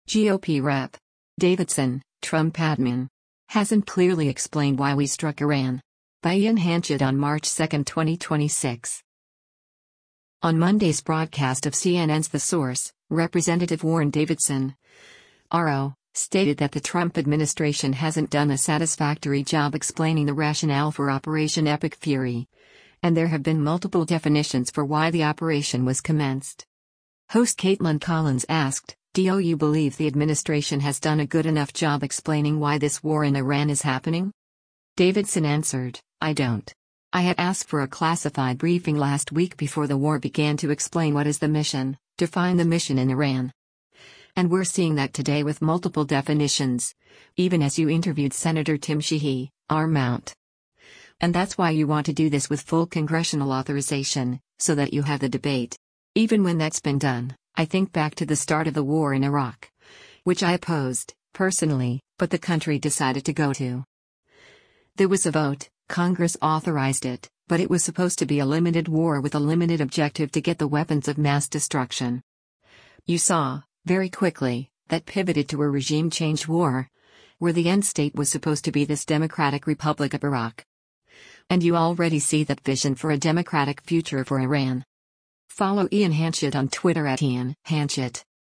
On Monday’s broadcast of CNN’s “The Source,” Rep. Warren Davidson (R-OH) stated that the Trump administration hasn’t done a satisfactory job explaining the rationale for Operation Epic Fury, and there have been “multiple definitions” for why the operation was commenced.